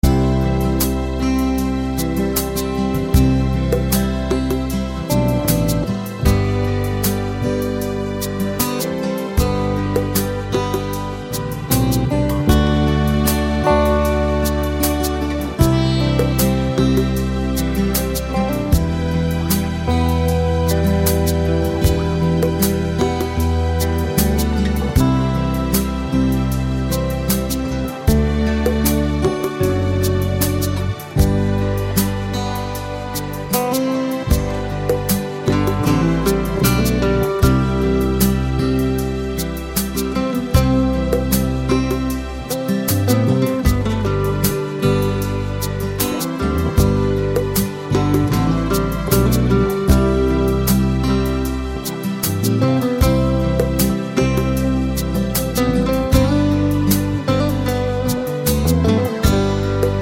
no Backing Vocals Easy Listening 3:41 Buy £1.50